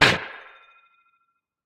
Minecraft Version Minecraft Version snapshot Latest Release | Latest Snapshot snapshot / assets / minecraft / sounds / mob / glow_squid / hurt3.ogg Compare With Compare With Latest Release | Latest Snapshot
hurt3.ogg